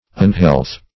Unhealth \Un"health\, n. Unsoundness; disease.